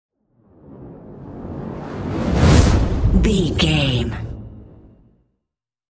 Whoosh to hit fire
Sound Effects
Atonal
intense
tension
the trailer effect